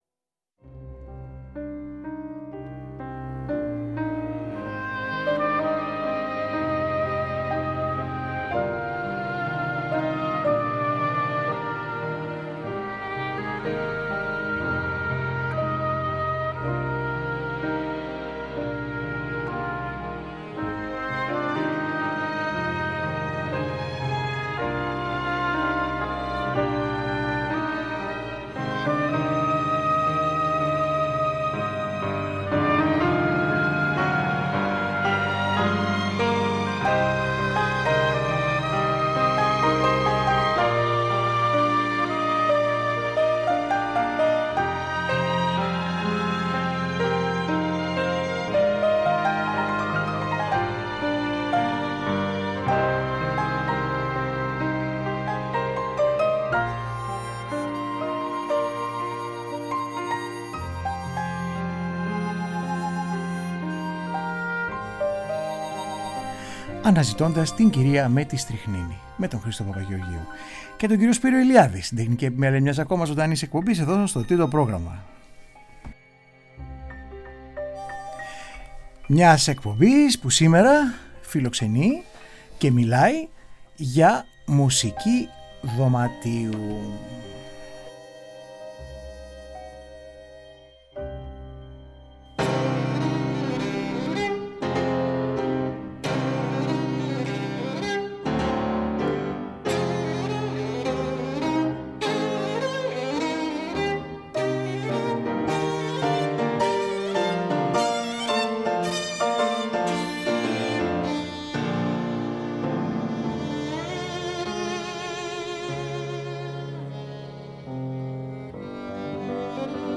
έρχεται ζωντανά στο Τρίτο για να παρουσιάσει το 1ο Ανεξάρτητο Φεστιβάλ Μουσικής Δωματίου “MUSEUPHORIA”